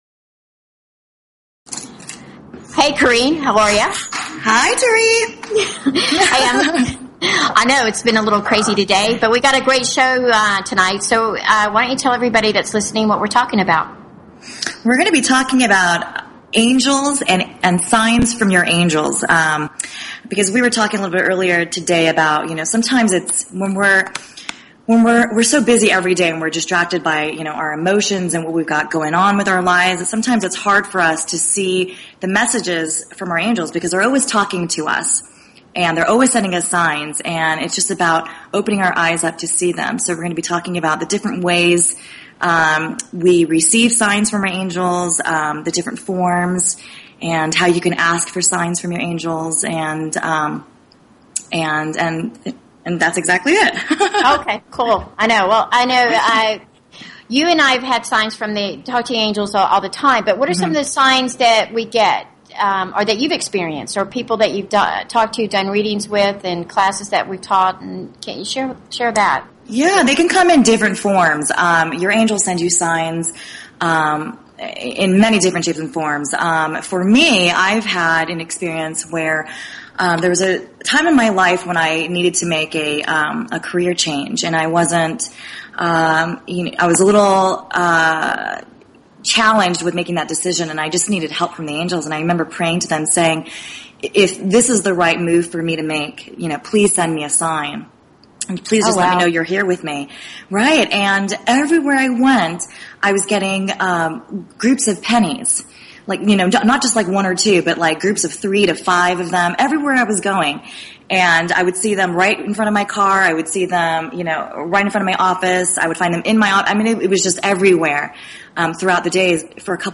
Talk Show Episode, Audio Podcast, Angel_Coaches and Courtesy of BBS Radio on , show guests , about , categorized as